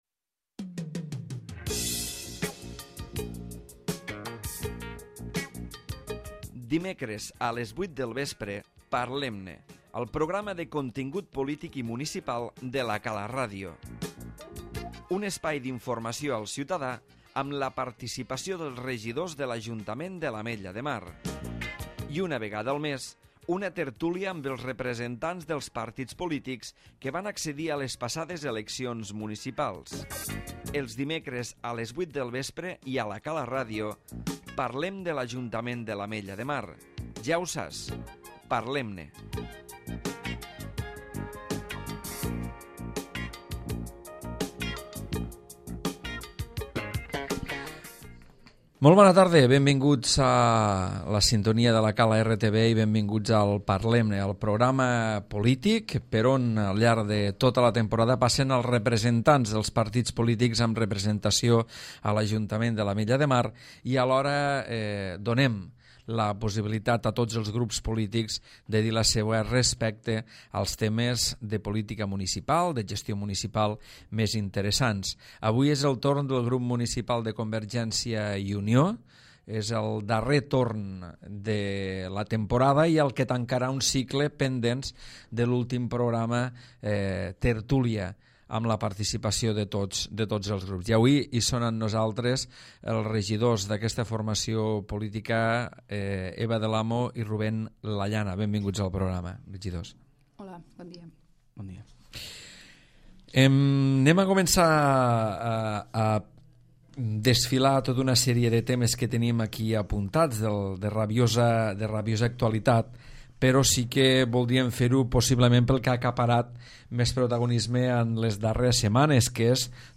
Ruben Lallana i Eva del Amo, regidors del Grup Municipal de CiU han participat al Parlem-ne, opinant i explicant els plantejaments d'aquesta formació política sobre els temes municipals de més actualitat.